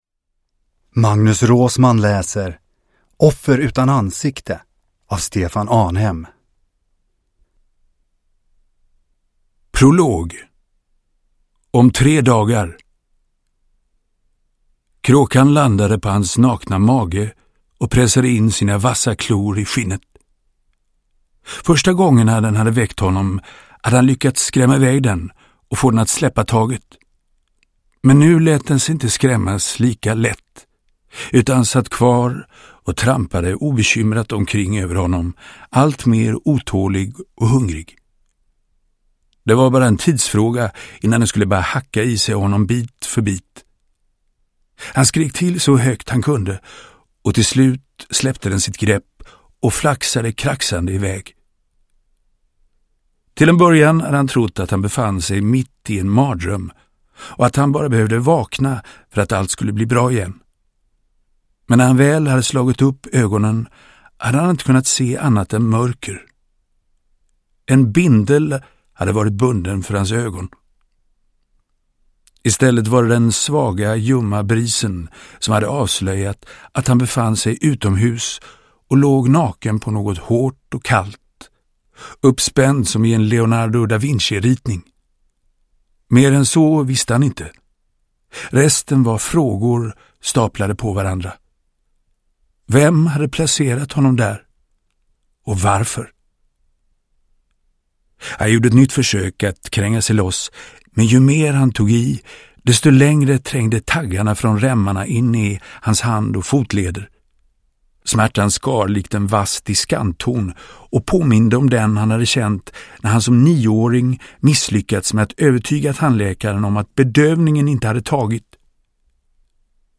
Offer utan ansikte – Ljudbok – Laddas ner
Uppläsare: Magnus Roosmann